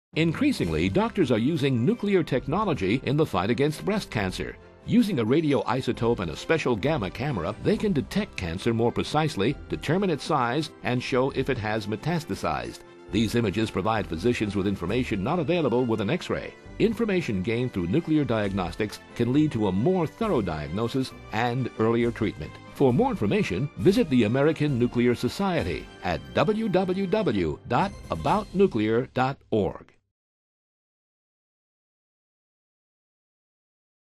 Public Service Announcements